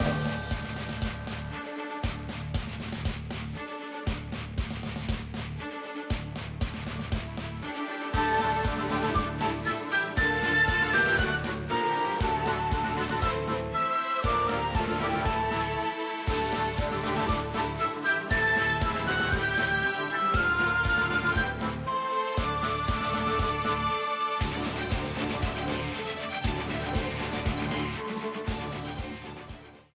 A theme
Ripped from the game
clipped to 30 seconds and applied fade-out